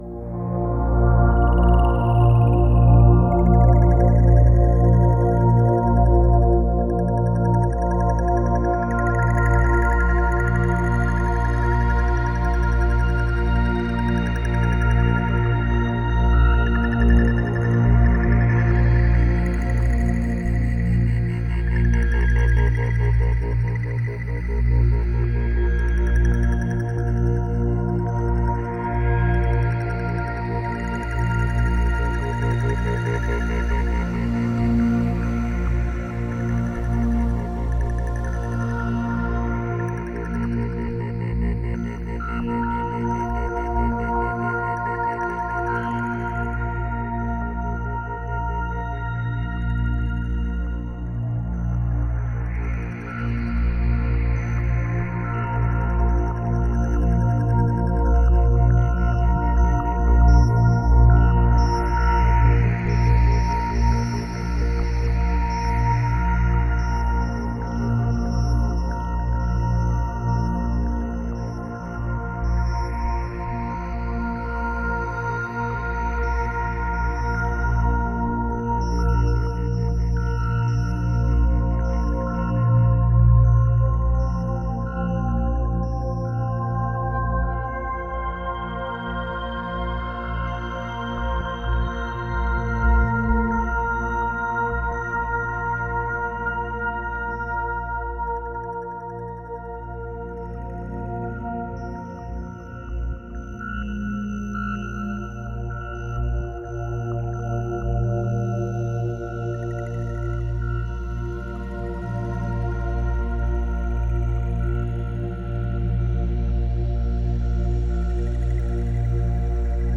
فرکانس 936 هرتز
936Hz.mp3